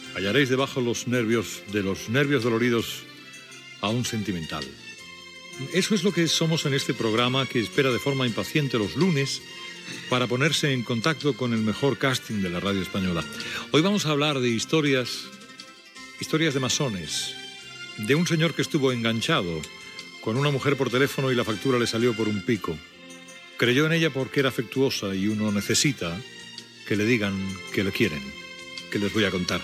Inici del programa de tarda
Entreteniment
FM